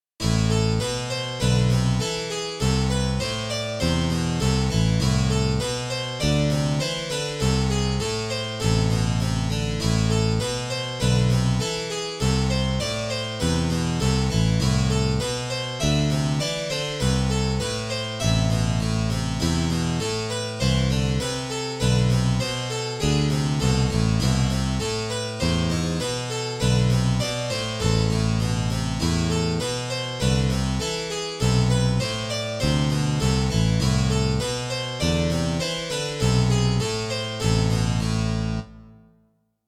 ハープシコード＋ベース